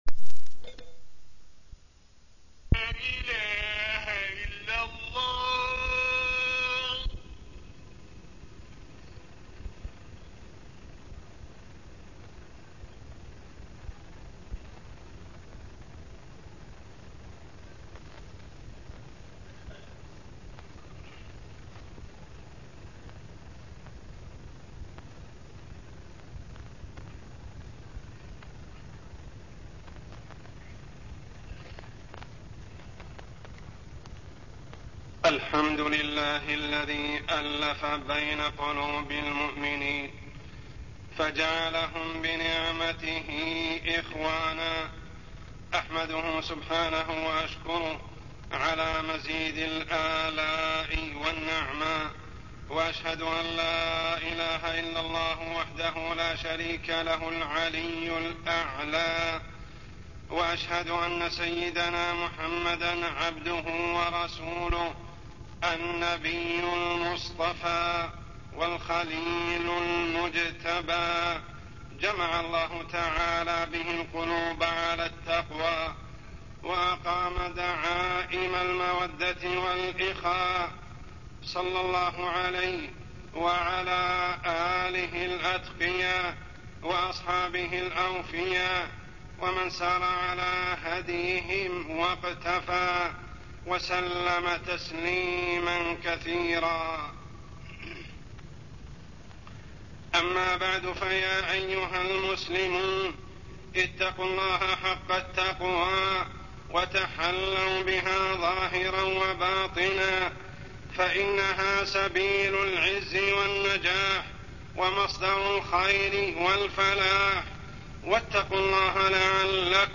تاريخ النشر ٢١ شعبان ١٤٢١ هـ المكان: المسجد الحرام الشيخ: عمر السبيل عمر السبيل وحدة الإمة